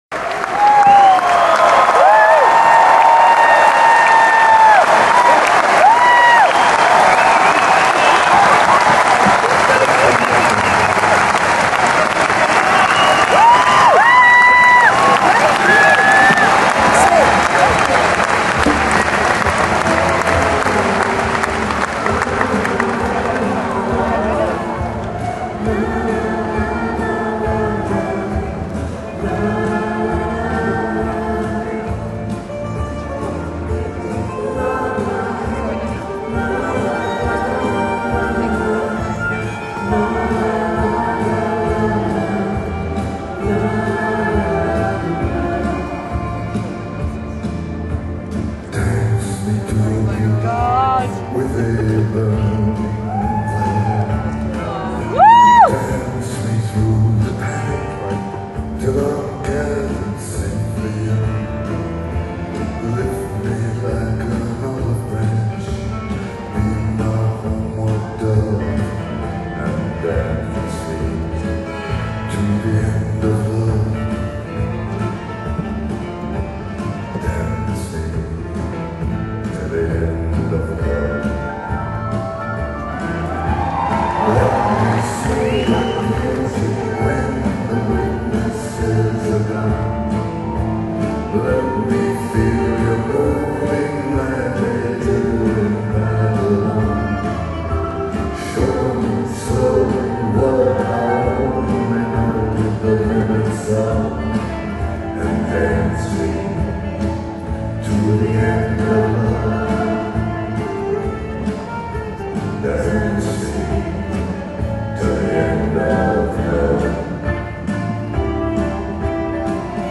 Genre: Rock, Blues, Folk, Live